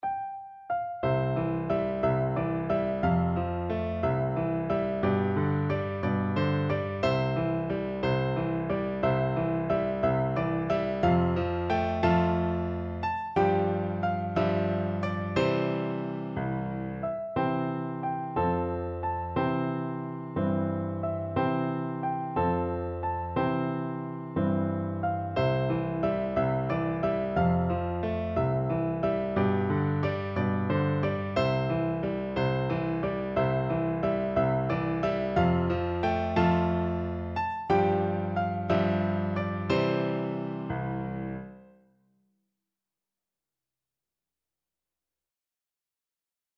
3/4 (View more 3/4 Music)
=180 Fast!
Piano Duet  (View more Beginners Piano Duet Music)